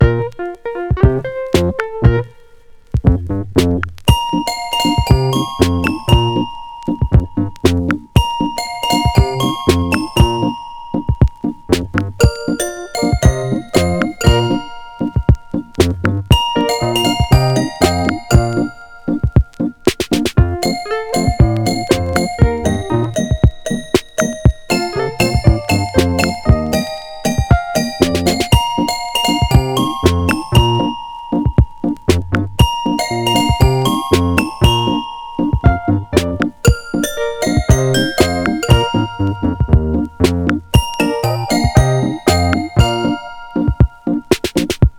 基本的にベースは、作詞作曲、ヴォーカル、コーラス、演奏と自作自演の多重録音で、宅録×80’sニューウェーブポップス。
Pop, Rock, New Wave　Netherlands　12inchレコード　33rpm　Stereo